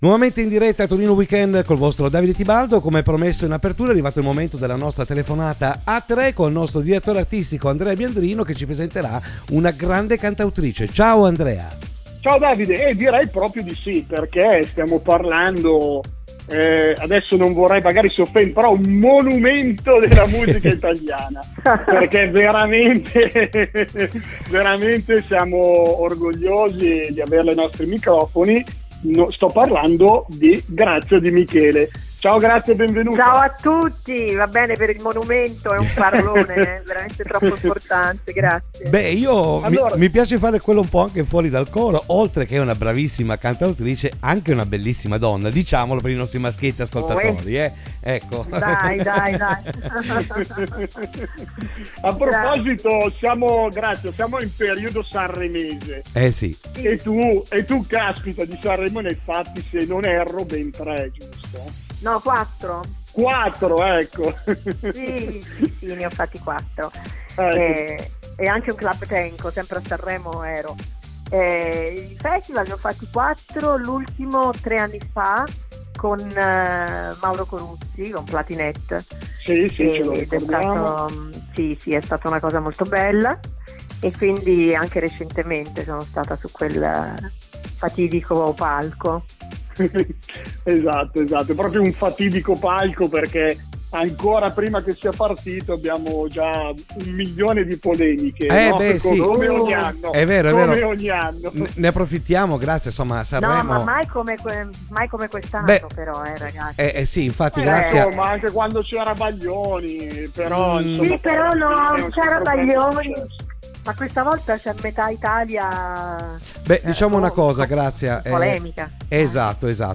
Ascolta l'intervista telefonica a Grazia Di Michele
intervista Grazia Di Michele.mp3